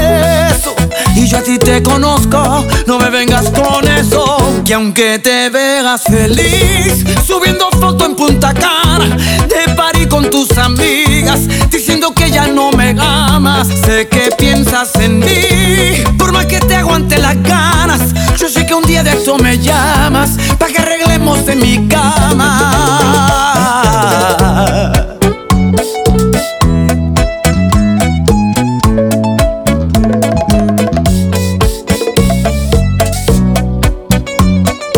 # Salsa and Tropical